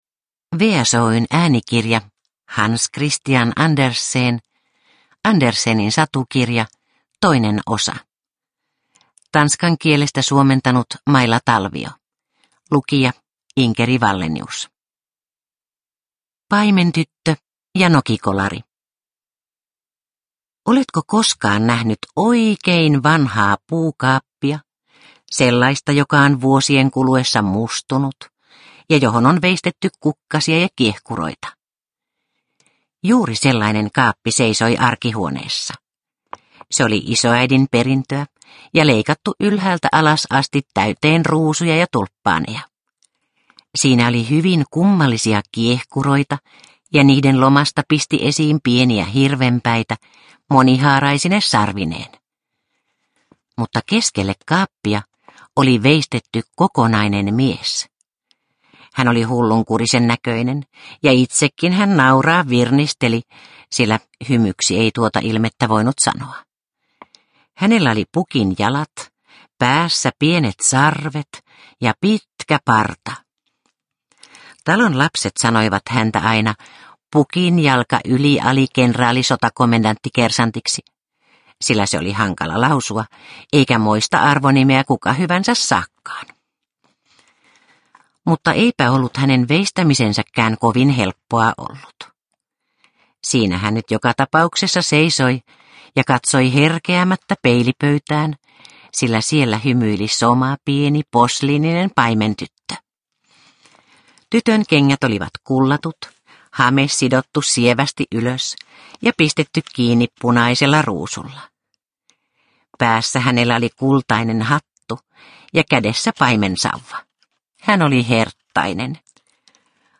Andersenin satukirja 2 – Ljudbok – Laddas ner